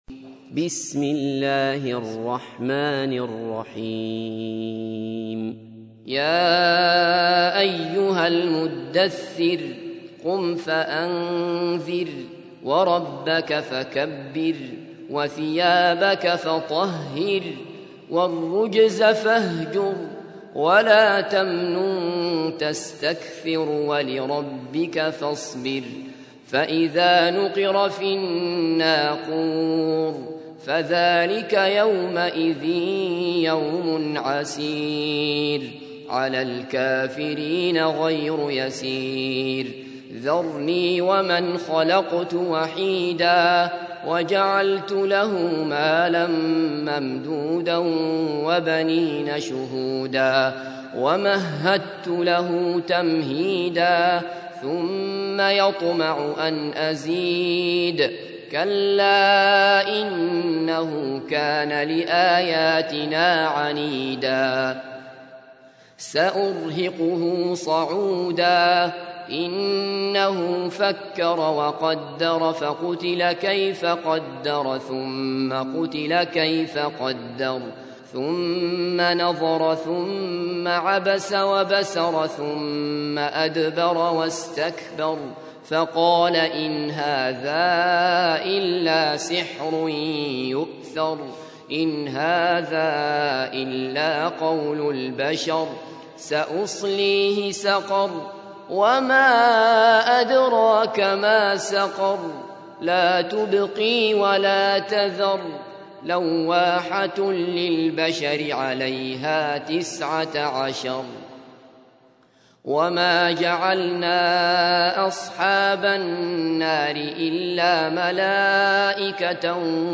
تحميل : 74. سورة المدثر / القارئ عبد الله بصفر / القرآن الكريم / موقع يا حسين